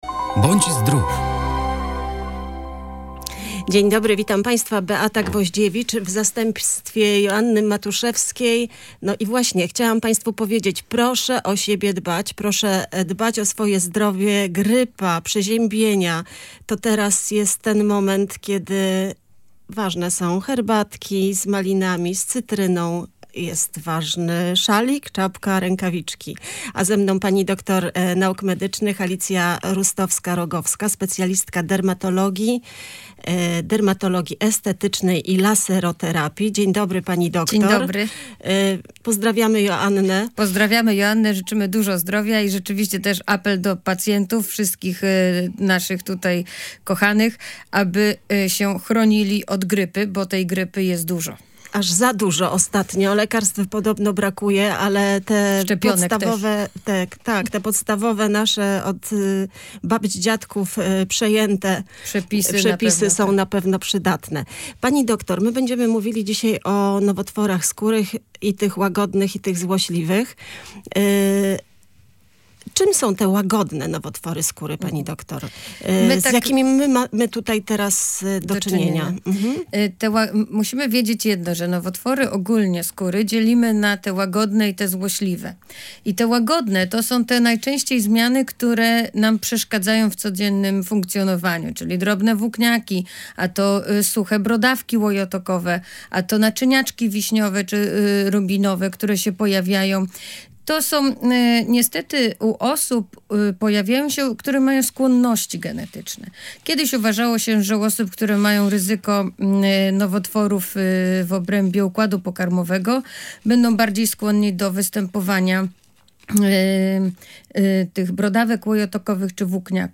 W audycji "Bądź zdrów" rozmawialiśmy o nowotworach skóry - i tych łagodnych, i tych złośliwych.